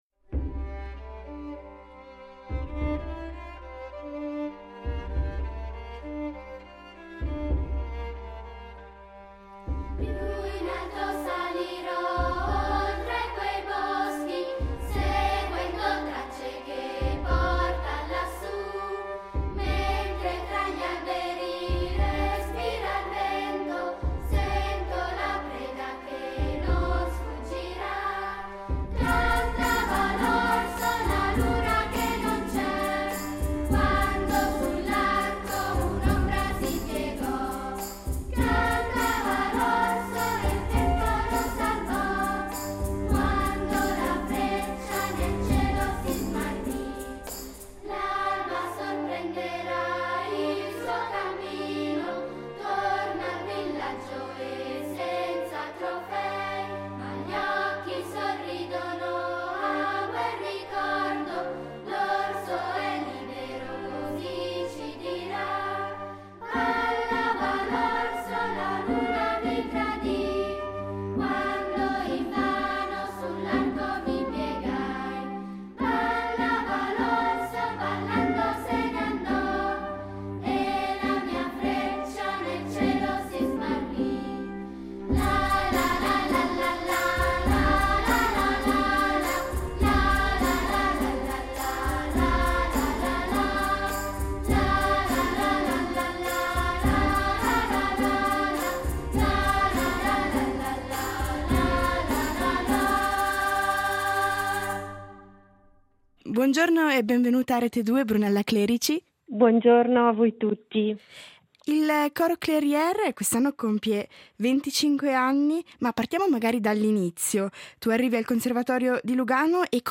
L’intervista arriva a pochi giorni dalla festa per i 25 anni del coro, celebrata domenica scorsa al LAC di Lugano , un momento di musica e memoria che ha riunito coristi, ex membri e pubblico in un grande abbraccio collettivo.